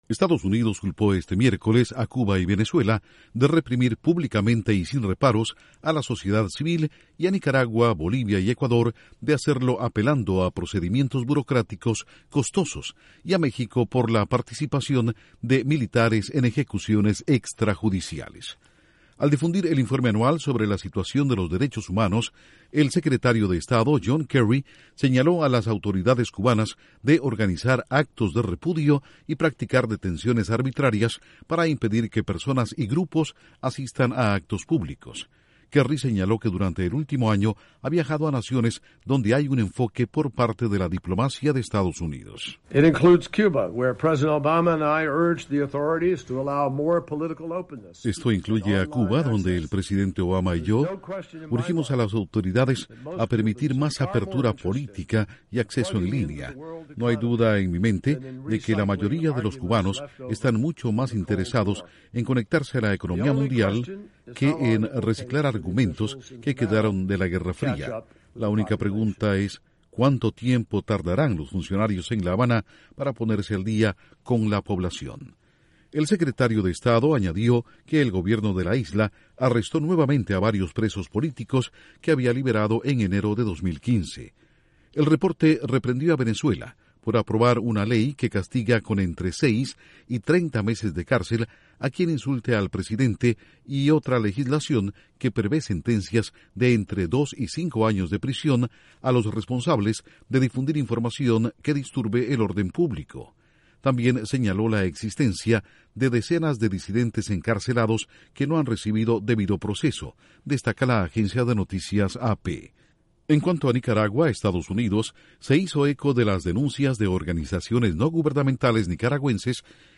Estados Unidos señala violaciones a los derechos humanos en seis países de América Latina. Informa desde Washington